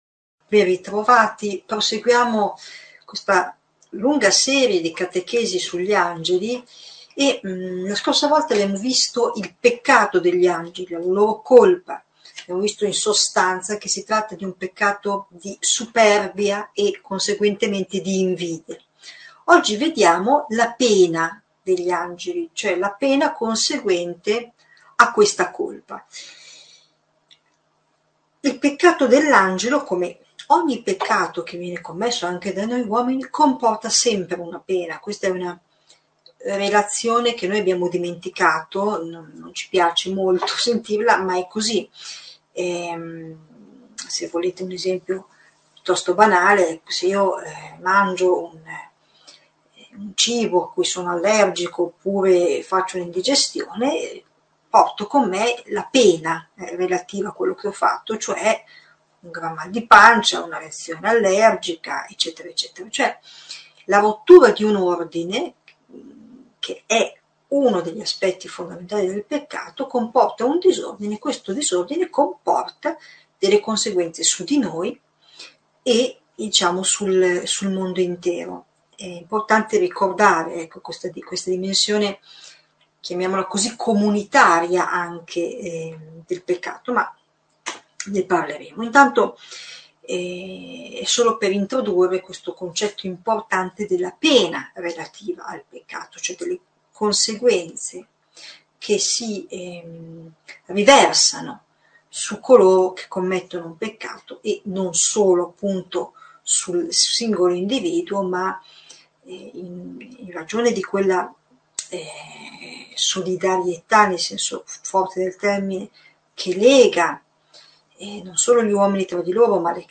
Catechesi adulti